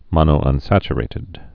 (mŏnō-ŭn-săchə-rātĭd)